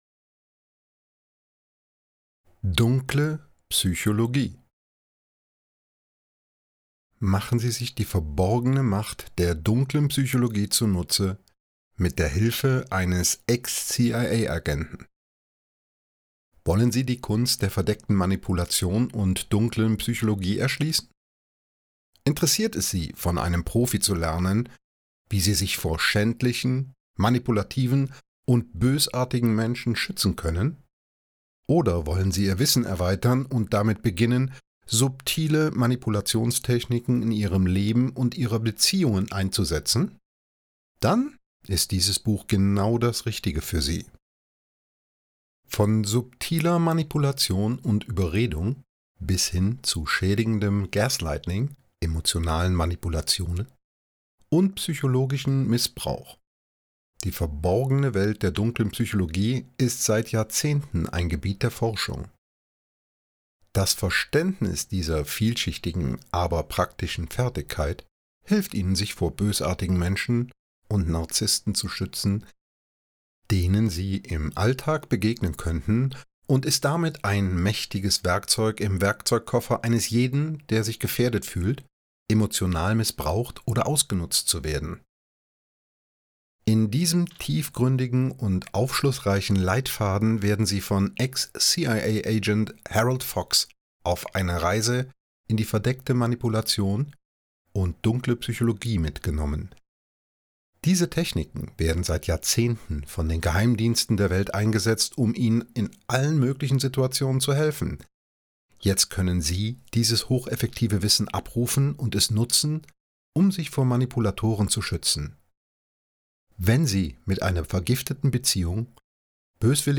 Einleitung Hörbuch